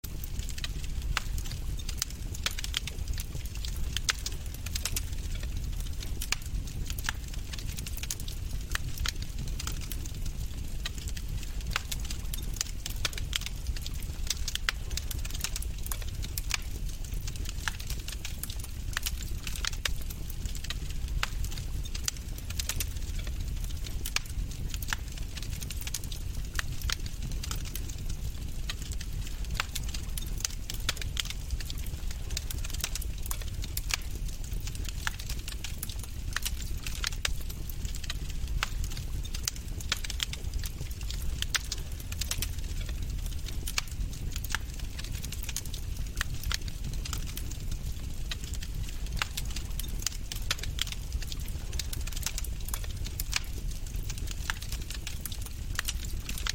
Fire
music_fire.Djsvu3LB.mp3